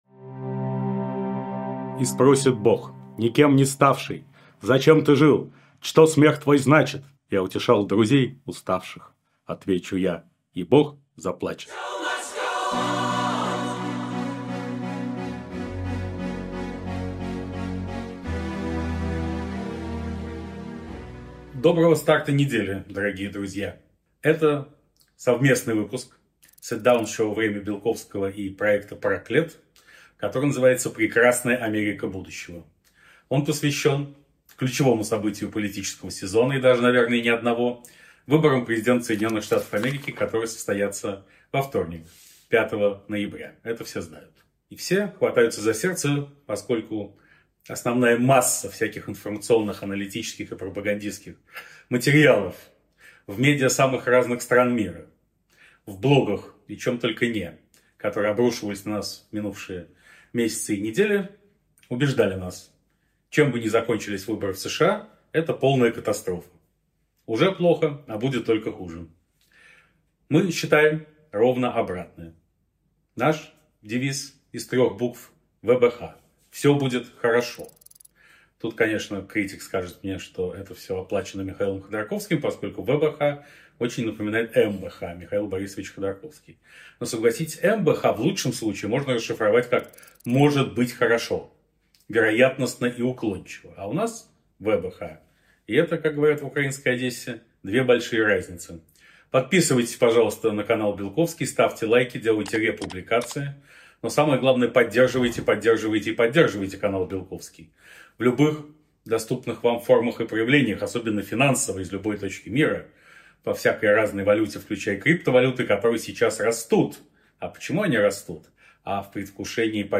Эфир ведёт Станислав Белковский